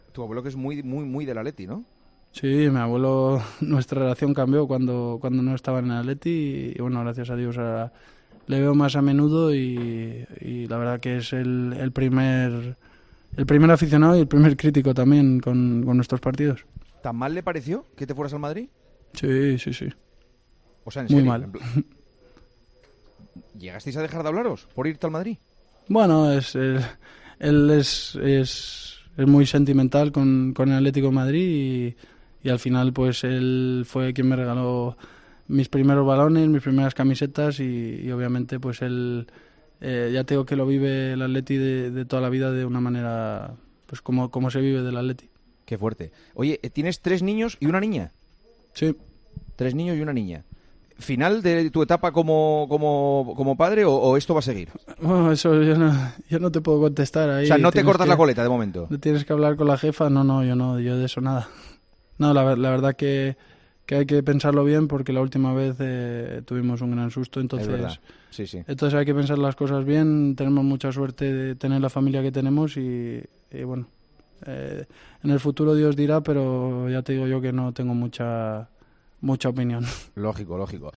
Hablando de todo un poco, en la conversación entre Juanma Castaño y Álvaro Morata en El Partidazo de COPE de este martes, salió la figura de su abuelo, un colchonero de pro.